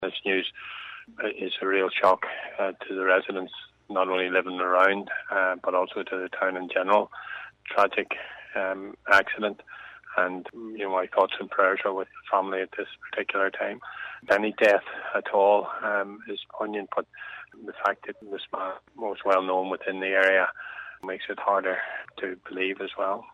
Councillor Trevor Wilson is Chair of Mid-Ulster District Council, he said the man was well-known in the area: